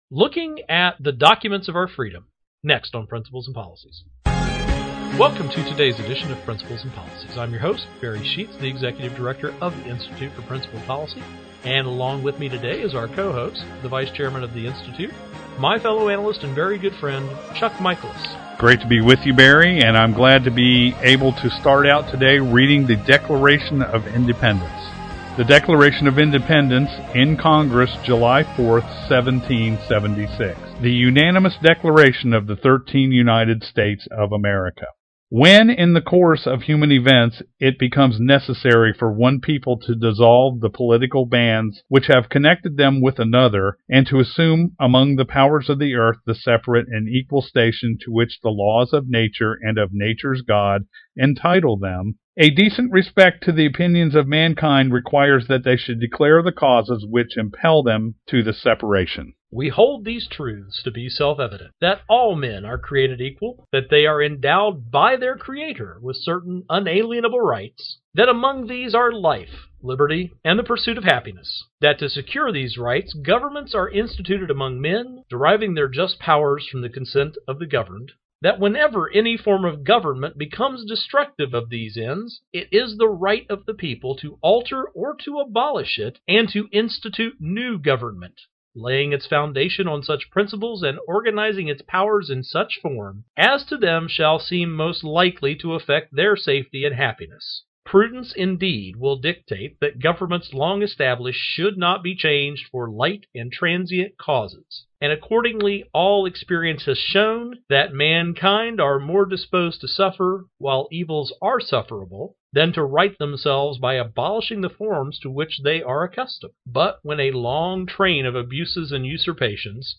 Our Principles and Policies radio show for Monday November 26, 2012.